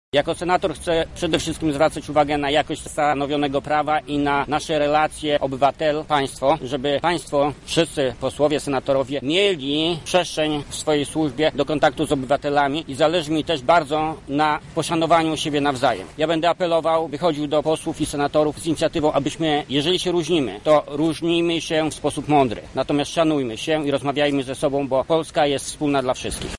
Zapytaliśmy przyszłego senatora, na co będzie zwracał szczególną uwagę, pełniąc swoją rolę: